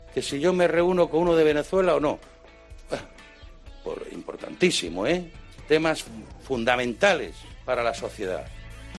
José Luís Ábalos sobre su encuentro con Delcy González